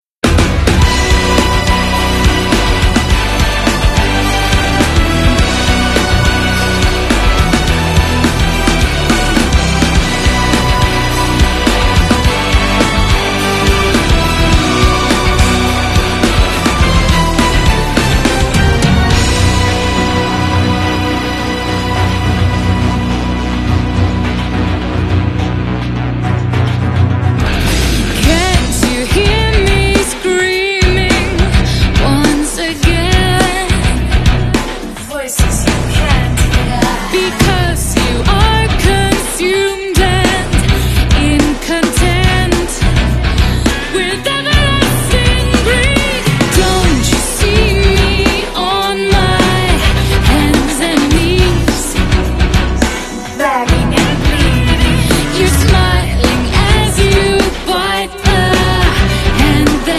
metal sinfônico
gothicmetal